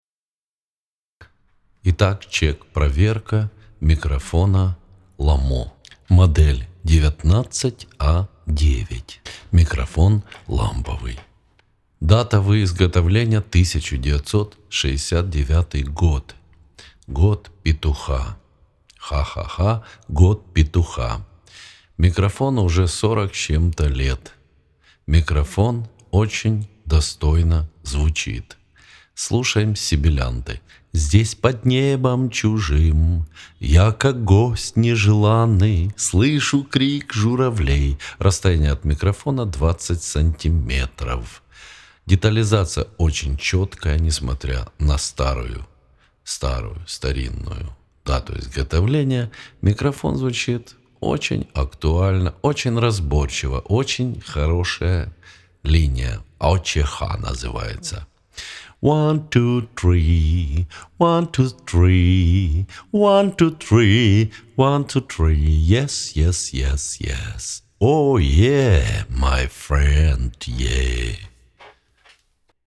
ЗНАЧИТ МИК ЛАМПОВЫЙ МОДЭЛЬ 1969 ГОДА НА ЭБЕИ 2 500 БАКСОВ.
ВОТ ЗВУК ЛОМО И ПРЕАМП ФОКУС РАЙ